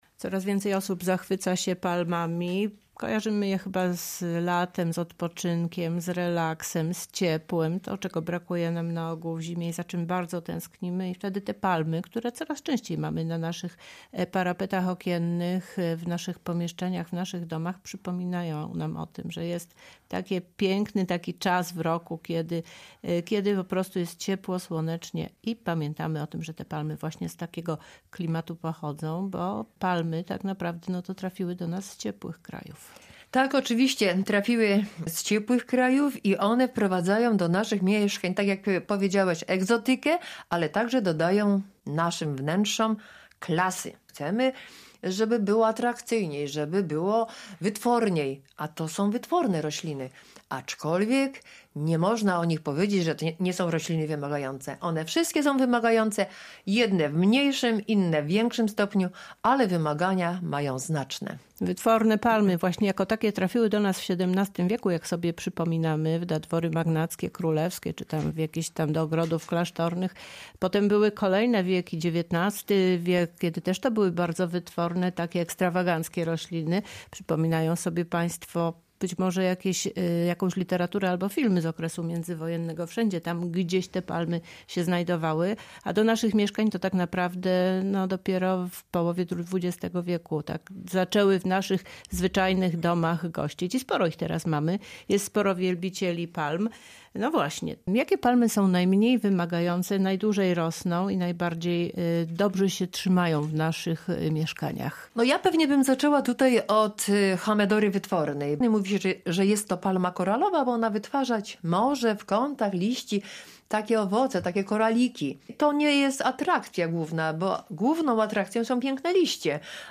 A więcej o palmach i o tym jak o nie dbać w rozmowie